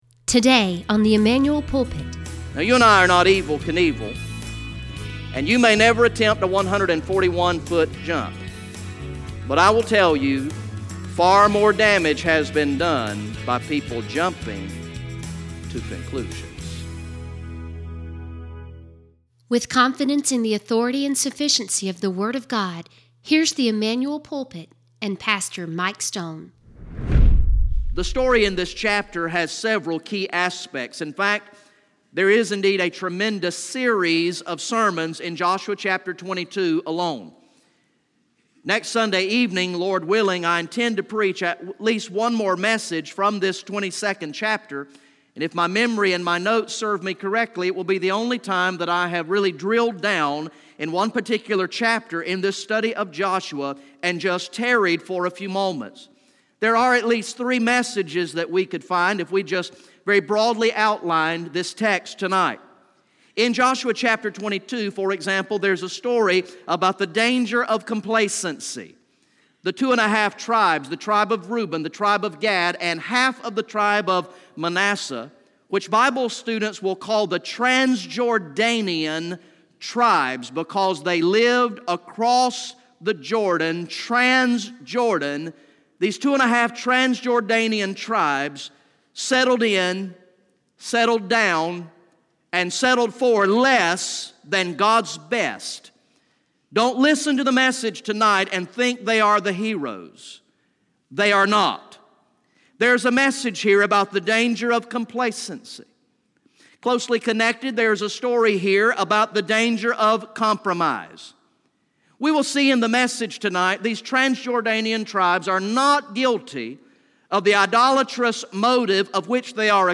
From the sermon series through the book of Joshua entitled "Walking in Victory" Recorded in the evening worship service on Sunday, March 11, 2018